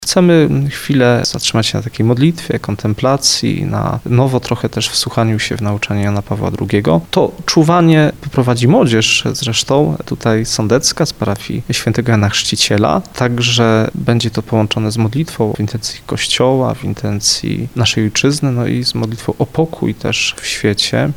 Czuwanie w Starym Sączu [ROZMOWA]